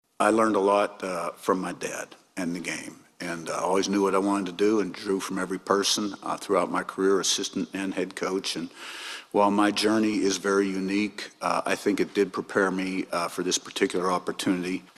Cignetti, who was 53-17 and won two PSAC championships in six seasons at IUP, said in the final news conference before the championship game that his father, Frank Cignetti Sr., showed him how to be a college football coach.